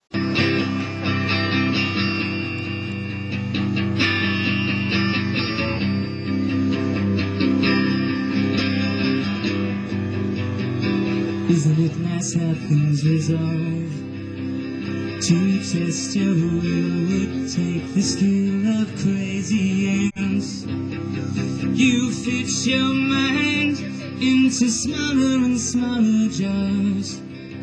Myrtle Edwards Park